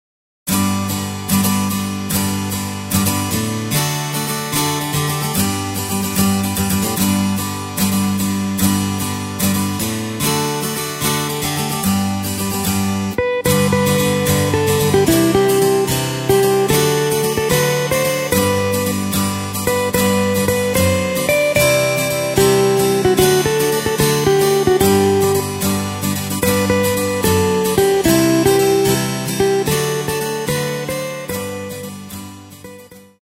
Takt:          4/4
Tempo:         148.00
Tonart:            G